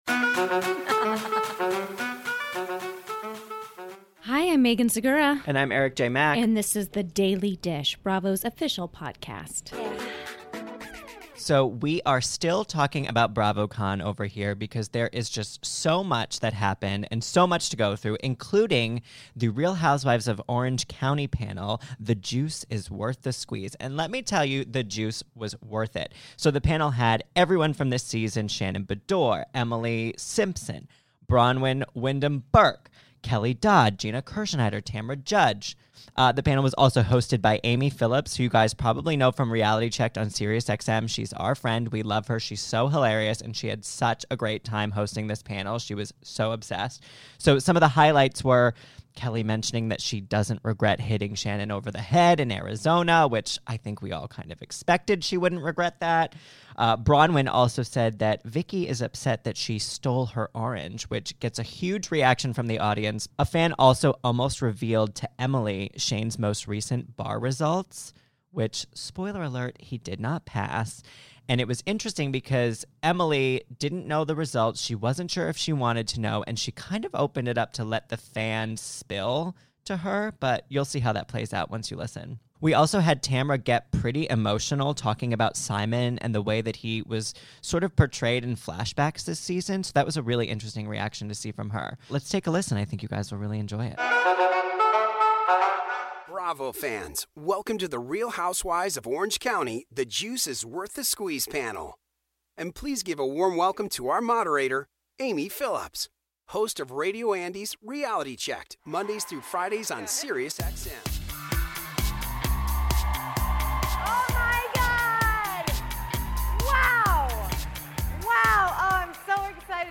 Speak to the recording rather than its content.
The ladies of Orange County answered hard-hitting questions during the RHOC: The Juice is Worth The Squeeze panel at BravoCon